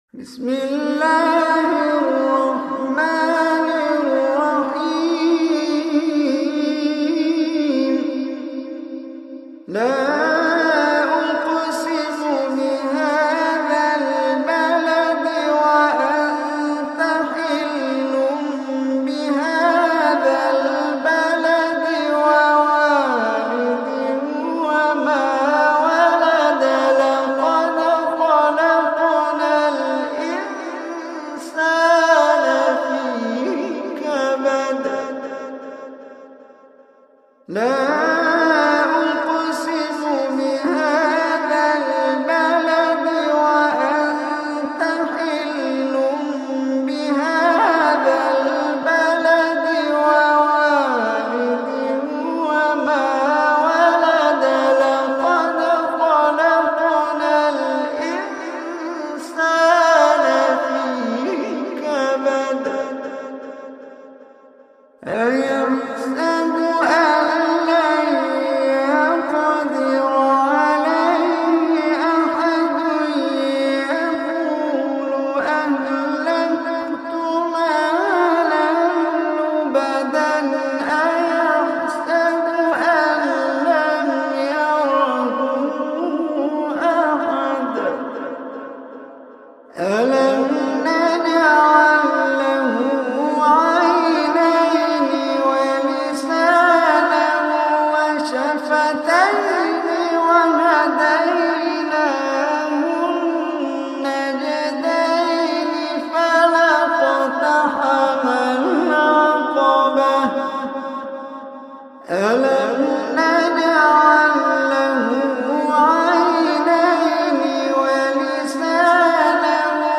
Surah Balad, is 90 surah of Holy Quran. Listen or play online mp3 tilawat / recitation in arabic in the beautiful voice of Omar Hisham Al Arabi.